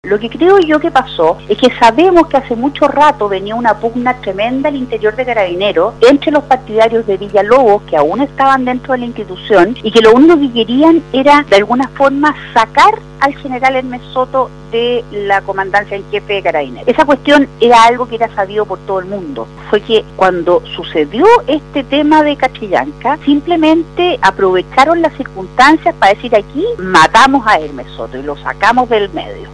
En conversación con Radio Sago, Gloria Naveillán acusó que caso Catrillanca se utilizó como excusa para destituir a Hermes Soto - RadioSago